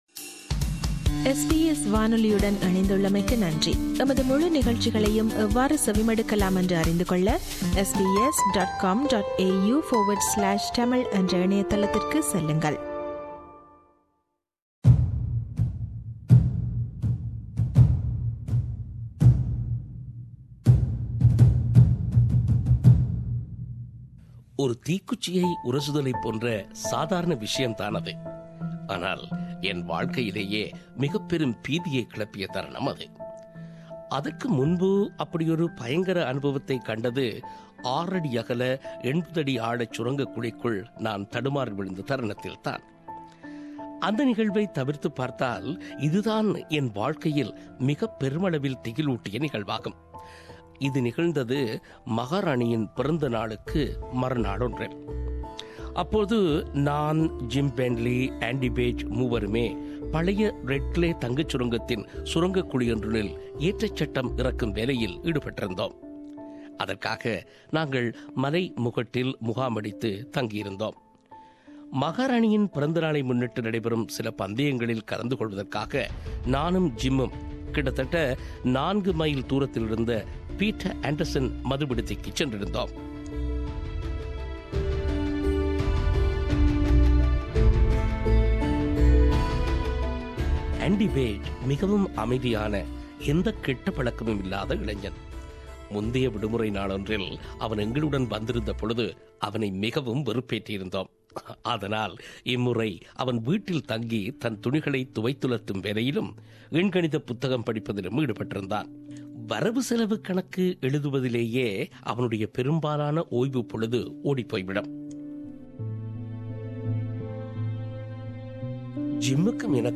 அந்தச் சிறுகதைகளில் சீனத்தவனின் ஆவி எனும் சிறுகதையை நாம் இங்கே ஒலிவடிவில் தருகிறோம்.